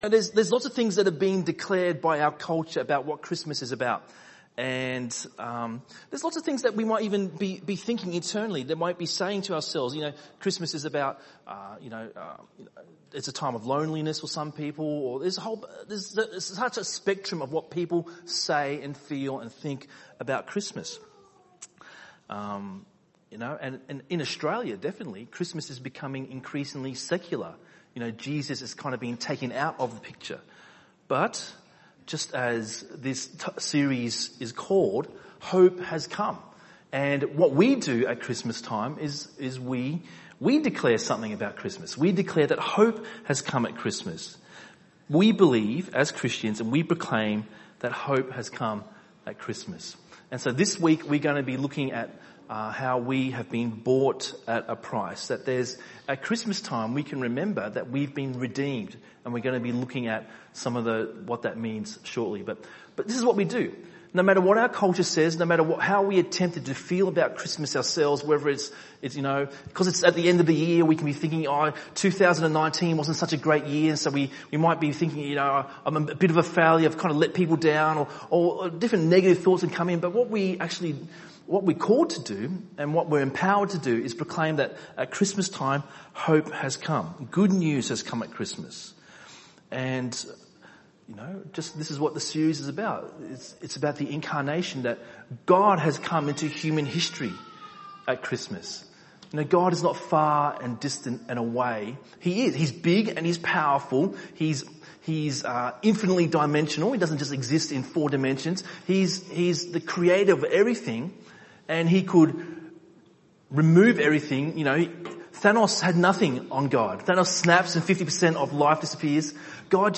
by enmelbourne | Dec 12, 2019 | ENM Sermon